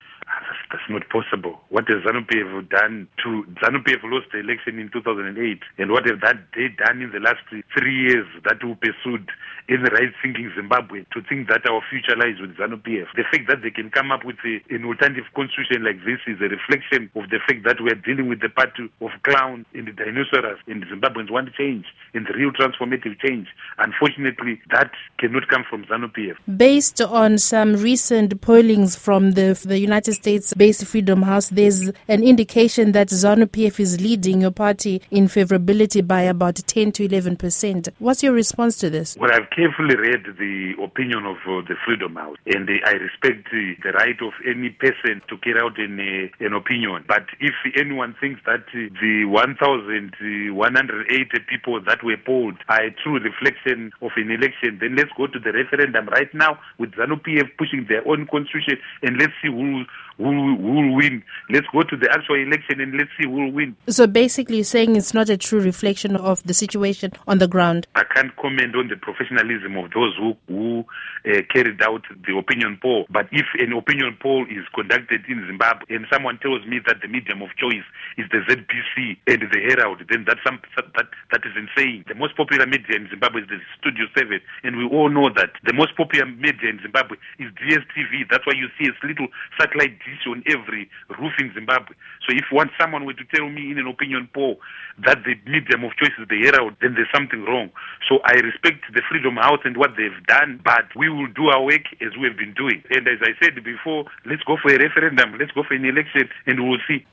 Interview With Tendai Biti